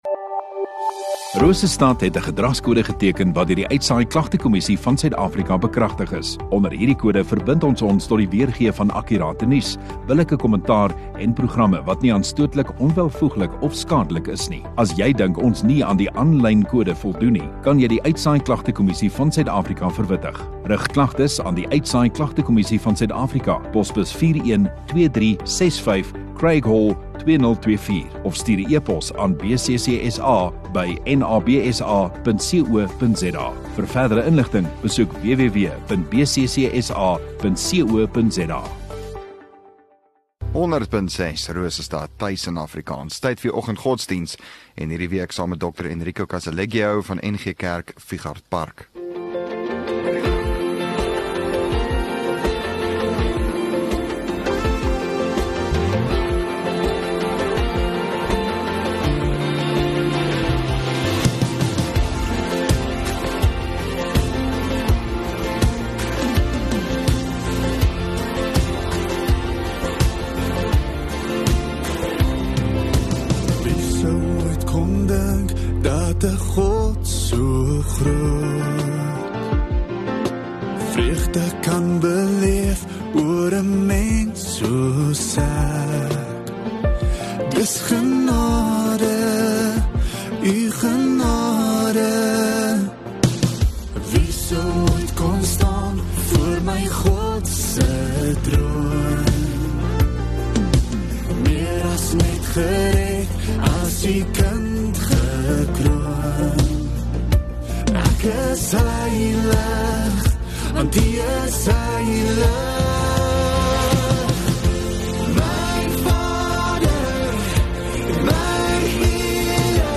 9 May Vyrdag Oggenddiens